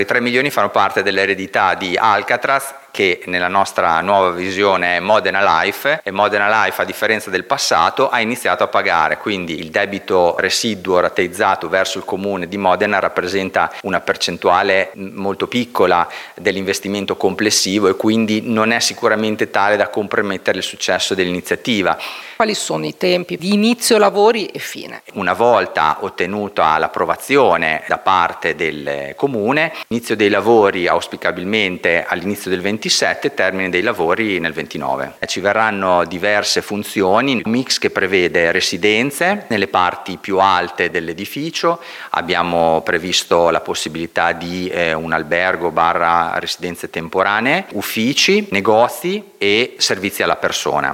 Alcatraz: intervista